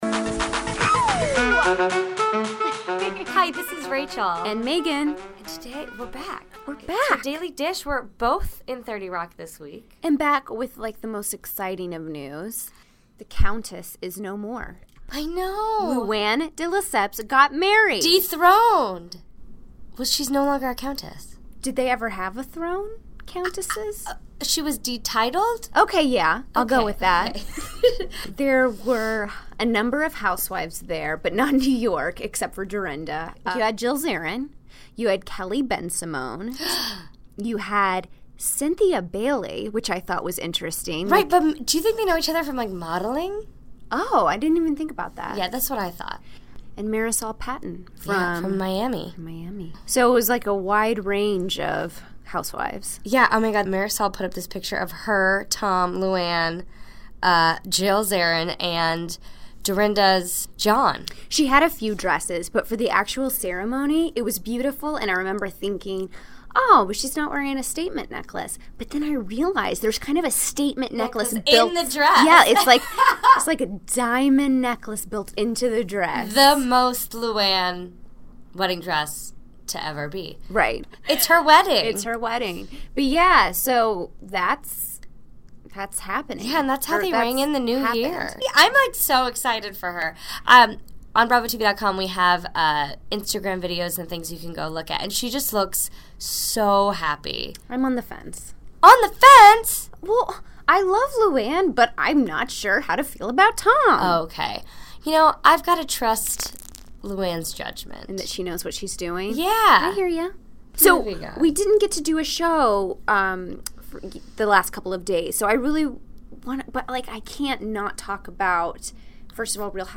Wednesday, January 4, 2016 - From Bravo HQ in New York City, we’re back and playing catch up with the biggest moments from RHOA, Vanderpump Rules, RHOBH, and Ladies of London. Plus - hear about Luann’s star-studded wedding and find out if she wore a statement necklace down the aisle!